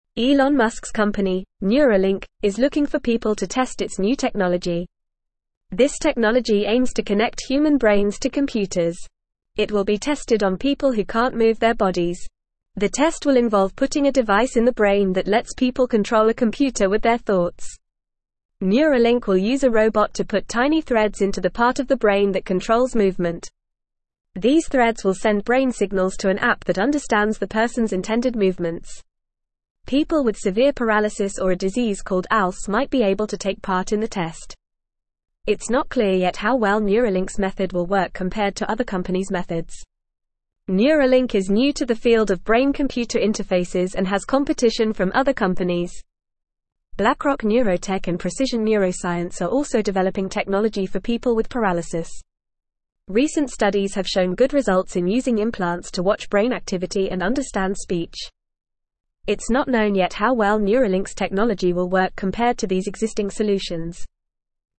Fast
English-Newsroom-Lower-Intermediate-FAST-Reading-New-Brain-Tool-Help-People-Move-Computers-with-Thoughts.mp3